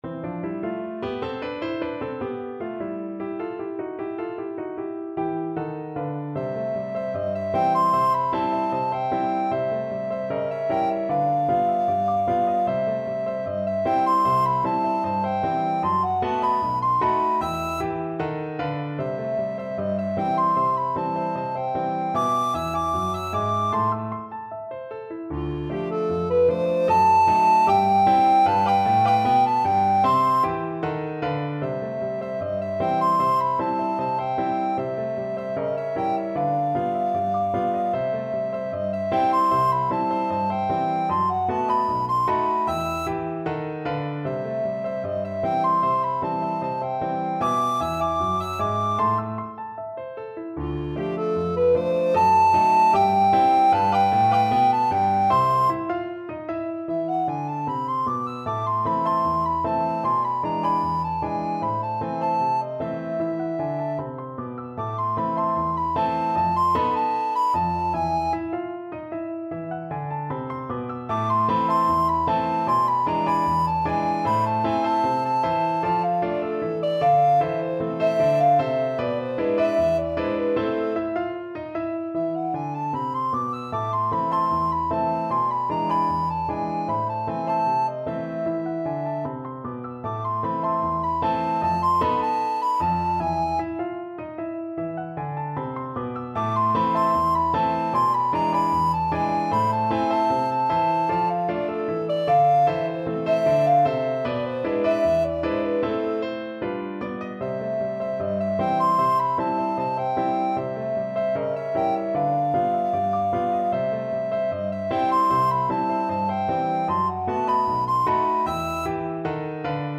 Free Sheet music for Alto (Treble) Recorder
Alto Recorder
C major (Sounding Pitch) (View more C major Music for Alto Recorder )
Allegro moderato. = 76 Allegro moderato (View more music marked Allegro)
2/4 (View more 2/4 Music)
Jazz (View more Jazz Alto Recorder Music)
scott_joplins_new_rag_AREC.mp3